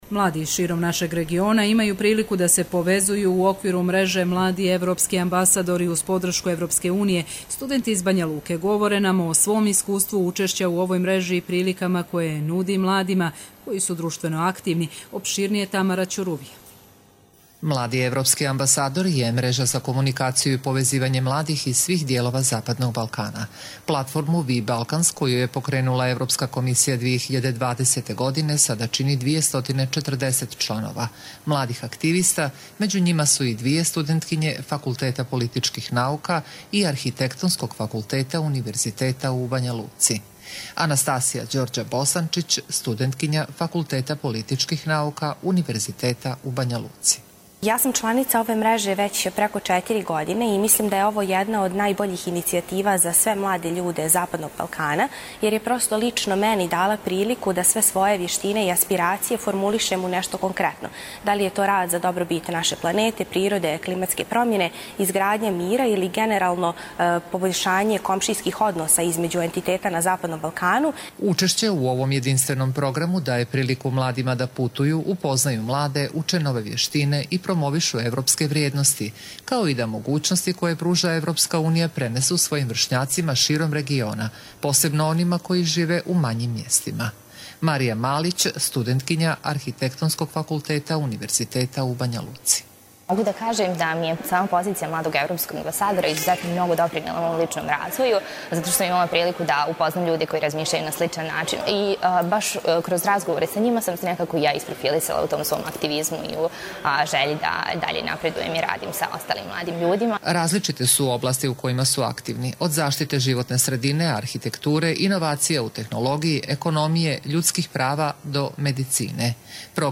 Radio reportaža